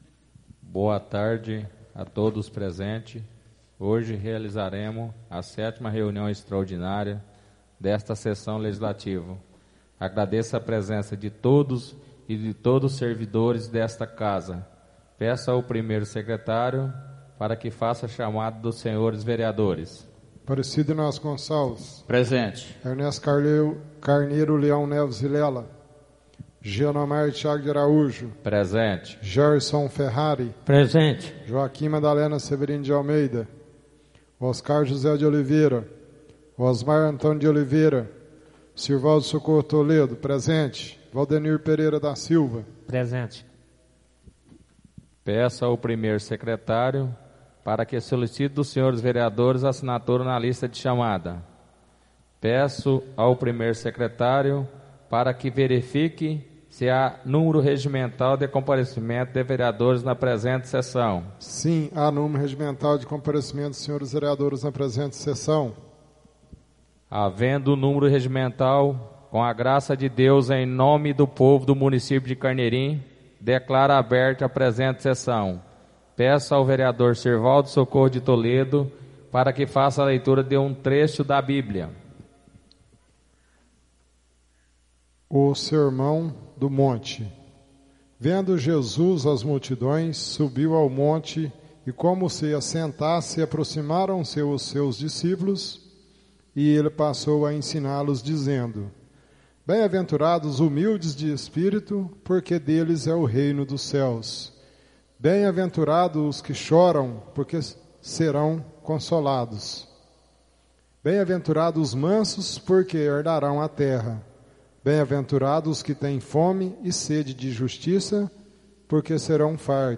Áudio da 7ª reunião extraordinária de 2016, realizada no dia 26 de Agosto de 2016, na sala de sessões da Câmara Municipal de Carneirinho, Estado de Minas Gerais.